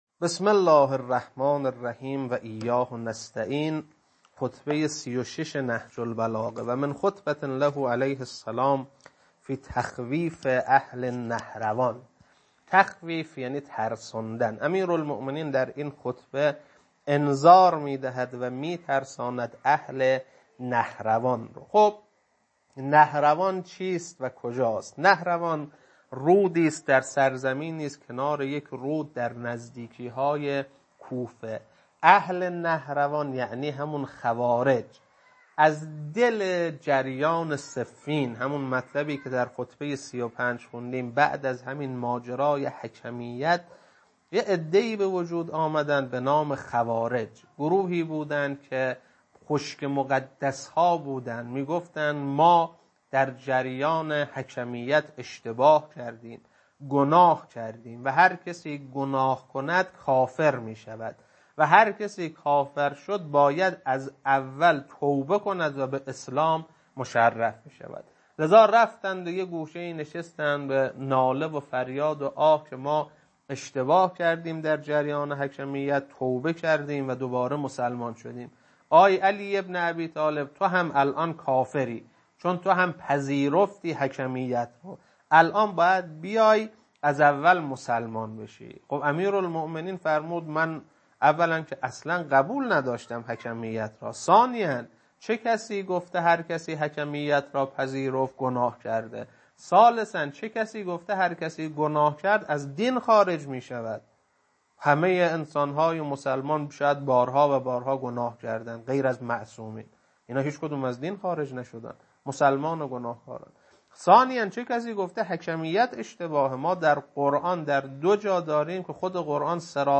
خطبه-36.mp3